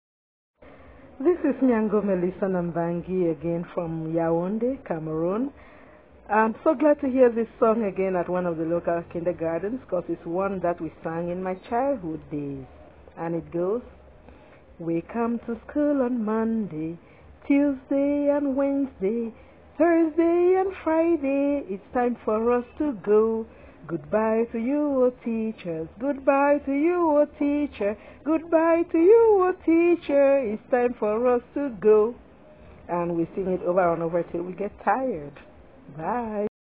We Come to School on Monday - Cameroonian Children's Songs - Cameroon - Mama Lisa's World: Children's Songs and Rhymes from Around the World